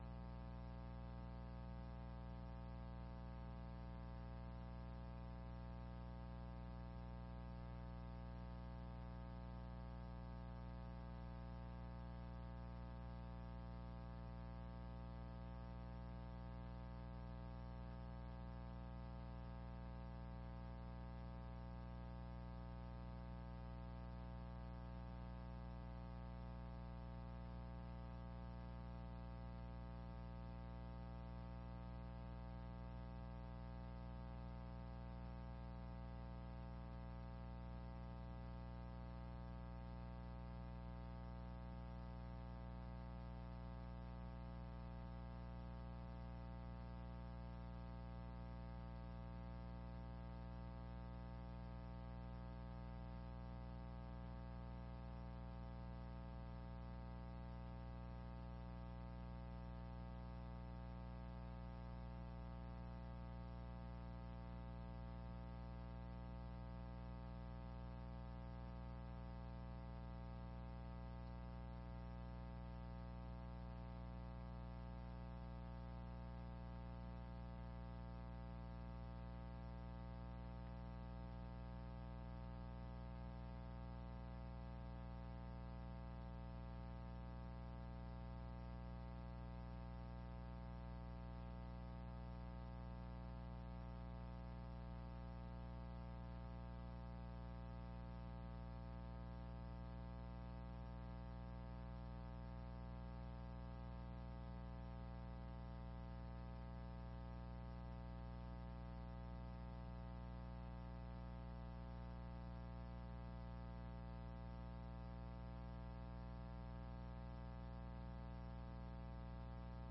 22ª Sessão Ordinária de 2018